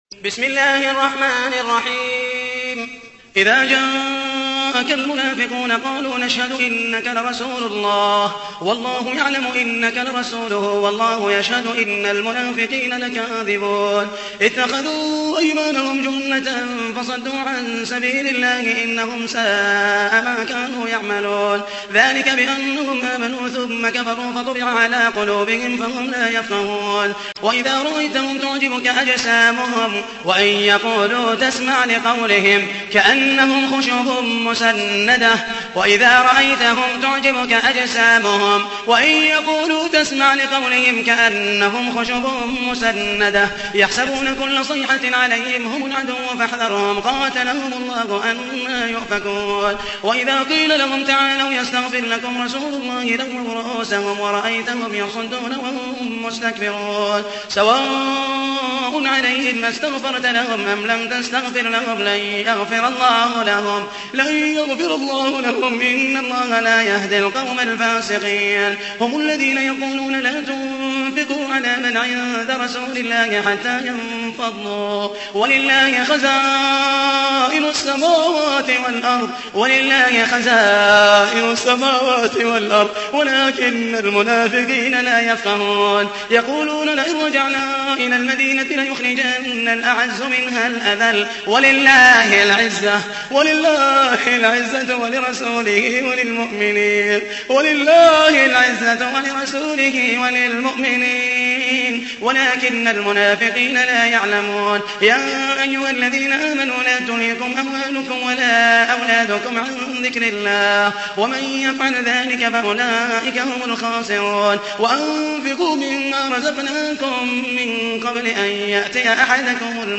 تحميل : 63. سورة المنافقون / القارئ محمد المحيسني / القرآن الكريم / موقع يا حسين